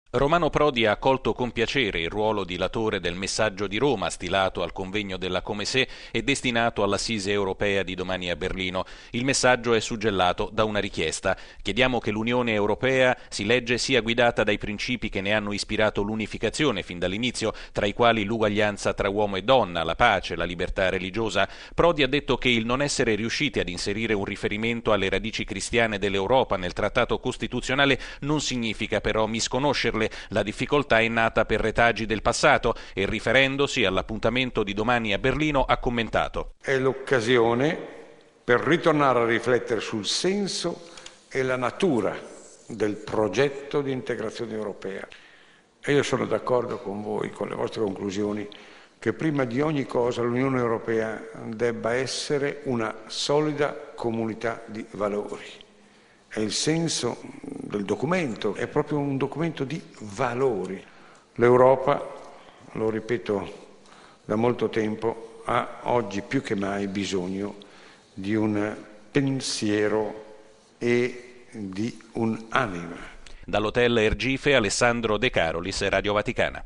Il “Messaggio di Roma”, com’è stato titolato, chiede in sostanza che il Vecchio continente “riconosca esplicitamente” la lunga eredità del suo “patrimonio cristiano”. Il Convegno si è chiuso nel tardo pomeriggio di oggi all’Hotel Ergife.